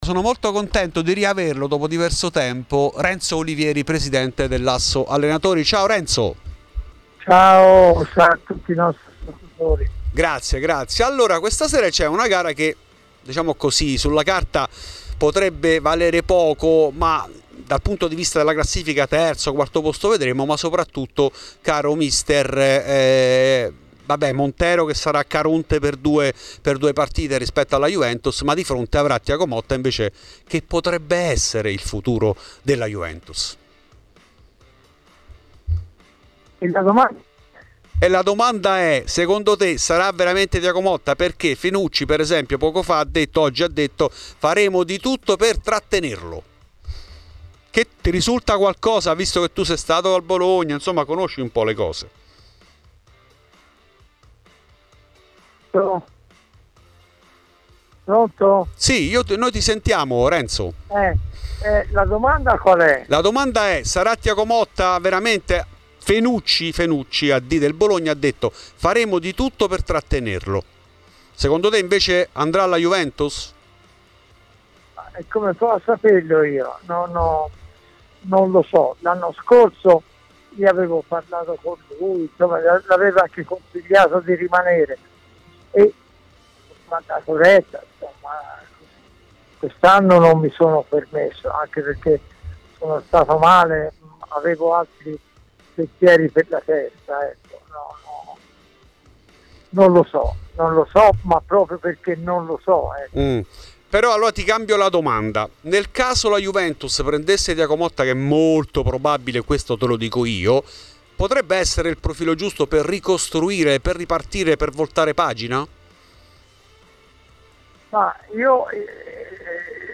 Nel podcast l'intervento integrale